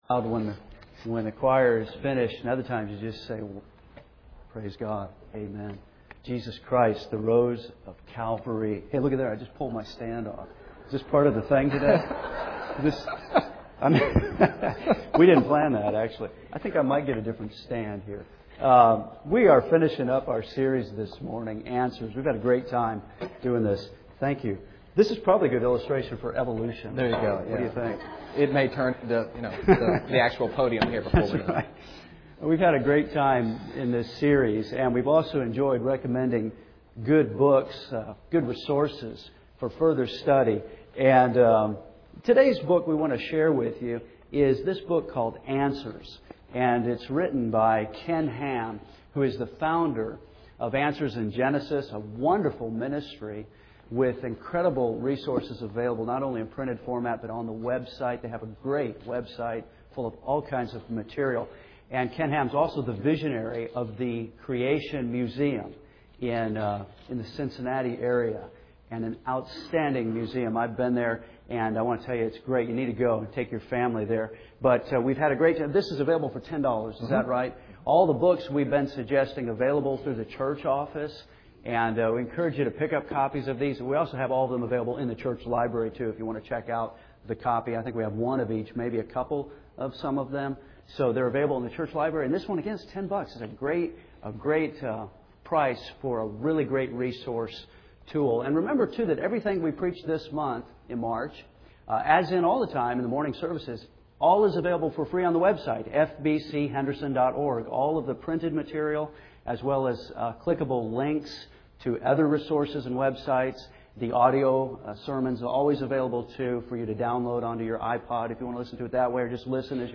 A Study in Evolution” (John 1:1-3) Series: Answers (5 of 5) Team Preaching: Revs.